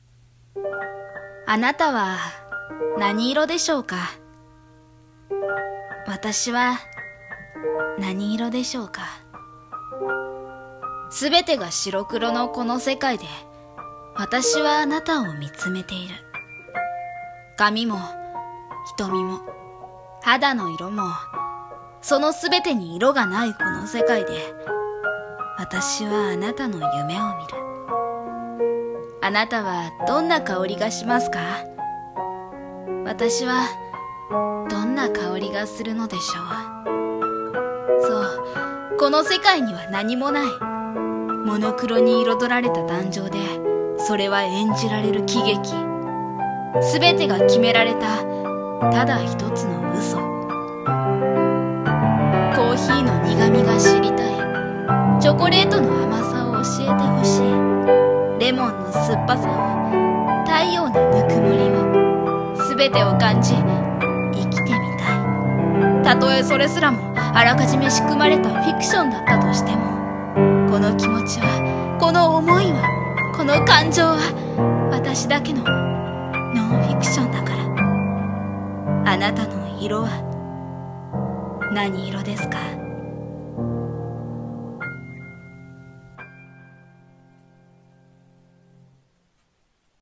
モノクロアクター【1人声劇】